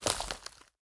drop_light.ogg